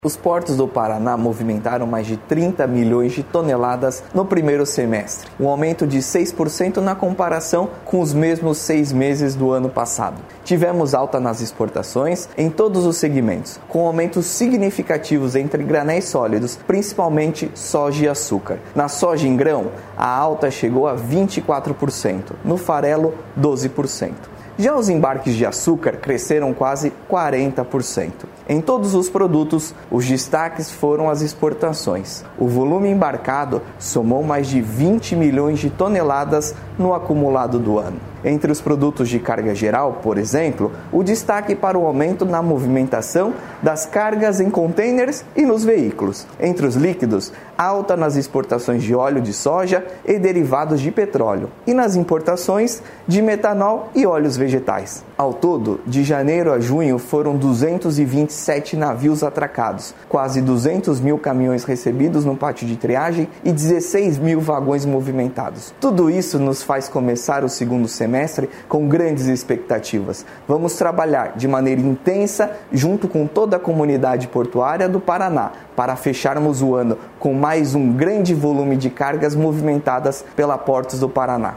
Sonora do diretor-presidente da Portos do Paraná, Luiz Fernando Garcia, sobre alta na movimentação dos portos no 1º semestre de 2023